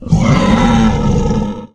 boar_death_1.ogg